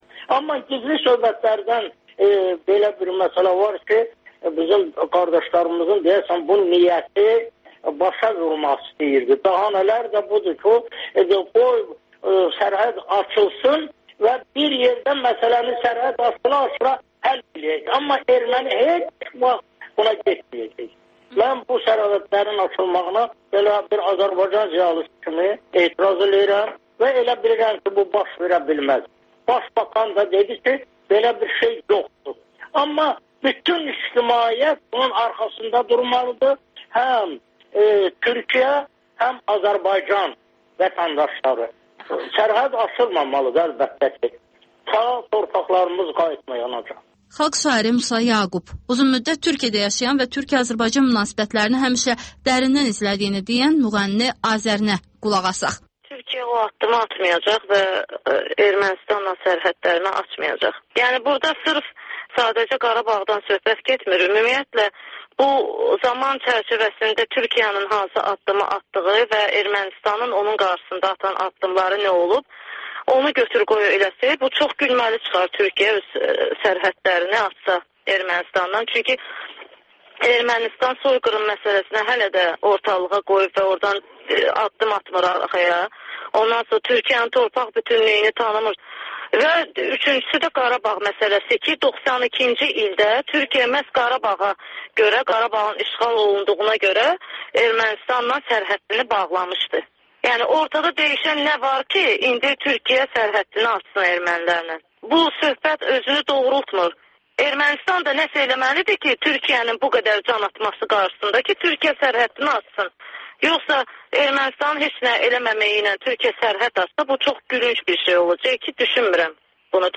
Ölkənin tanınmış simaları ilə söhbət Təkrar